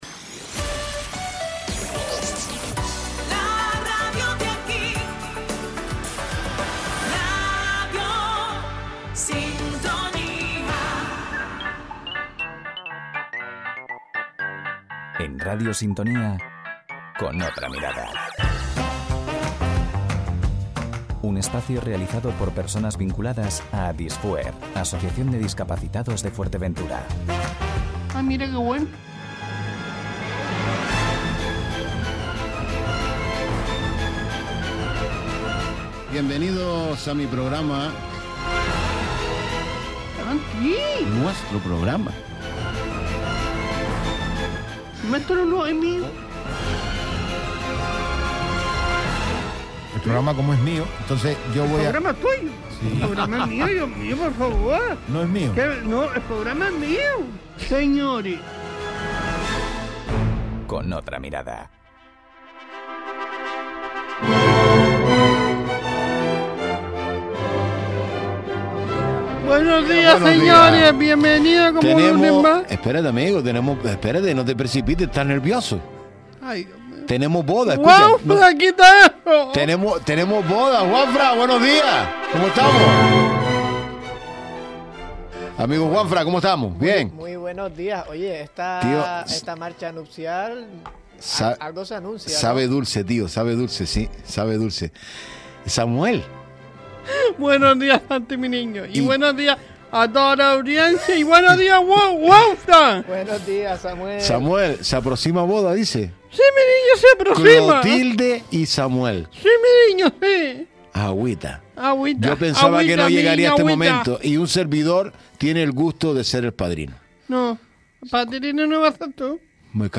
Hoy visita el programa Con Otra Mirada el consejero de acción social del Cabildo de Fuerteventura, Víctor Alonso.